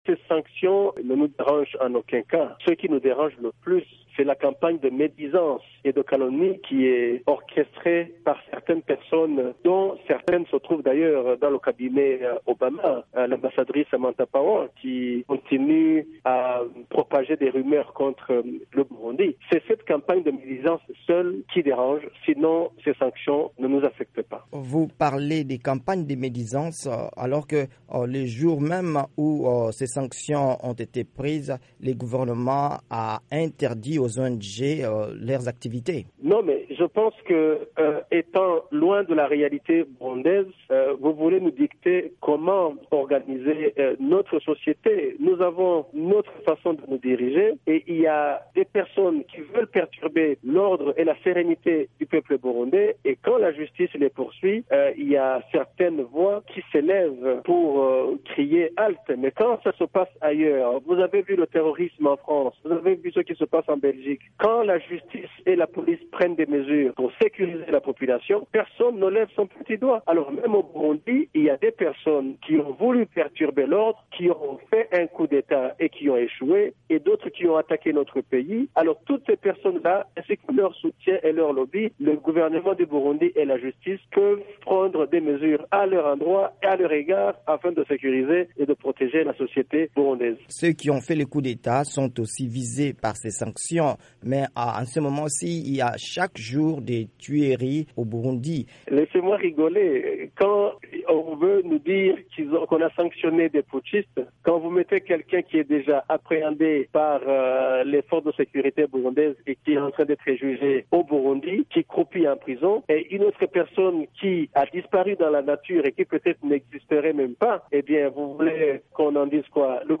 Dans une interview à VOA Afrique, le porte-parole du président Pierre Nkurunziza, Willy Nyamitwe dénonce la "campagne de médisance et de calomnie" que son gouvernement considère être derrière les sanctions contre de hauts responsables burundais.